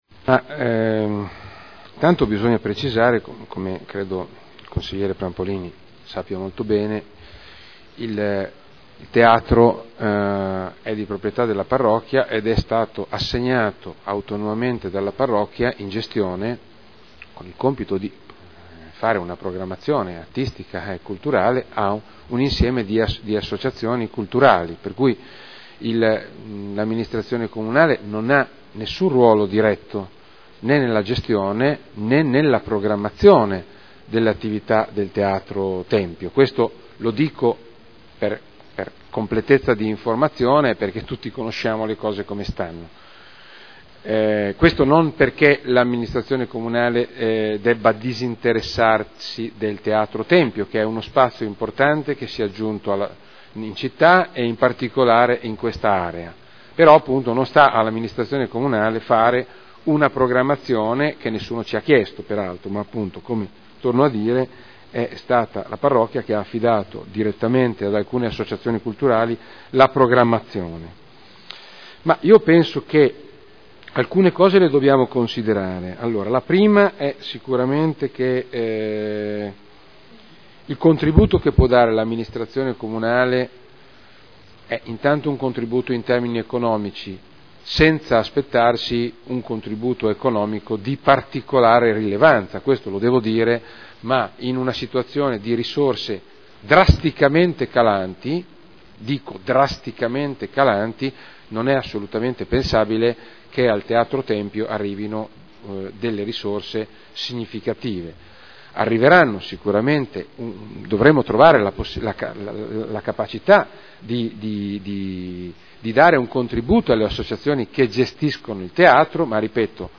Roberto Alperoli — Sito Audio Consiglio Comunale